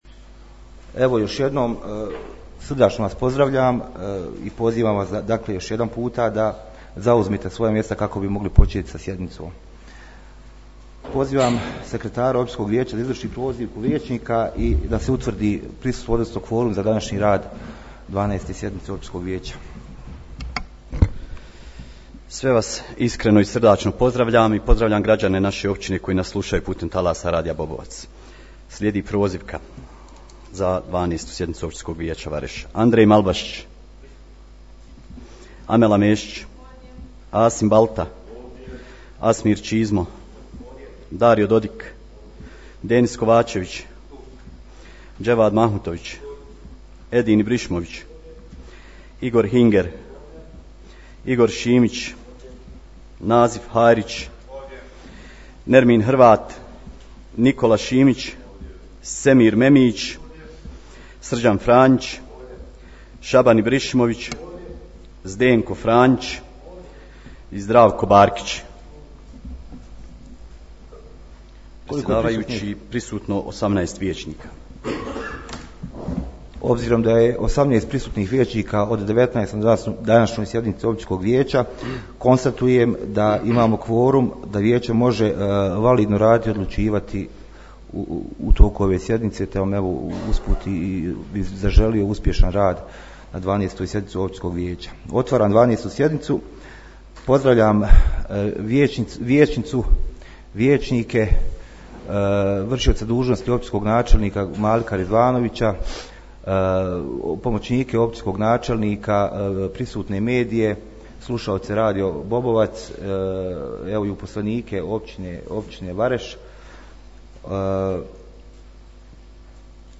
Poslušajte tonski zapis 12. sjednice Općinskog vijeća Vareš na kojoj su izabrani predsjedavajući vijeća Igor Šimić i zamjenica predsjedavajućeg Amela Mešić.